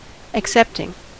Ääntäminen
Synonyymit acceptance Ääntäminen US : IPA : [ækˈsɛptɪŋ] Tuntematon aksentti: IPA : /ək.ˈsɛp.tɪŋ/ Haettu sana löytyi näillä lähdekielillä: englanti Käännöksiä ei löytynyt valitulle kohdekielelle.